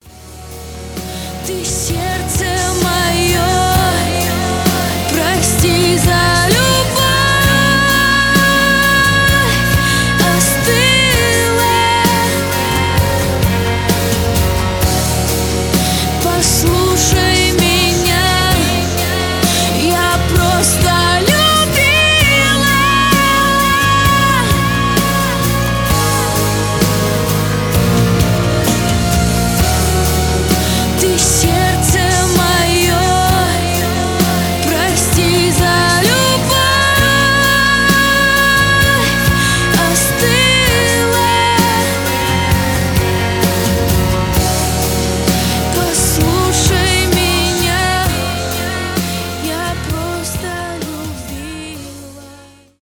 романтические , медленные , поп
красивый вокал